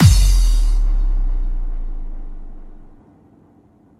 Session 14 - Kick _ Crash.wav